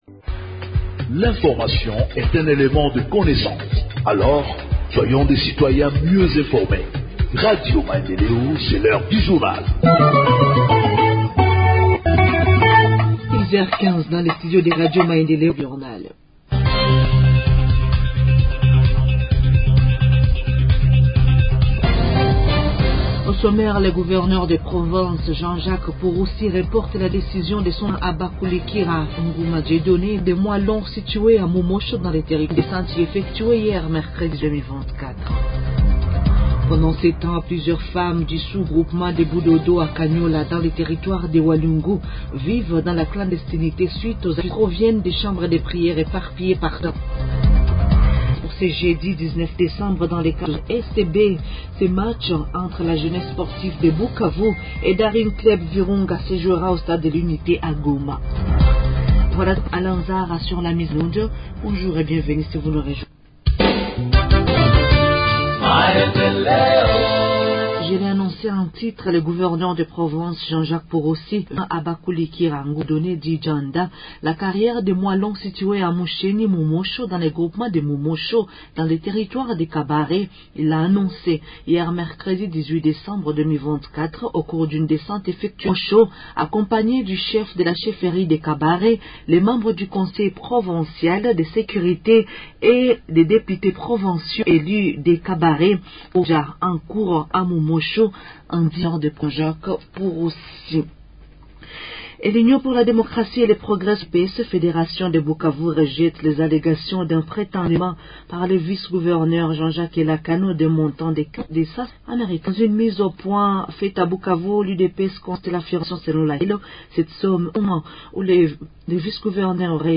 Journal en Français du 19 Décembre 2024 – Radio Maendeleo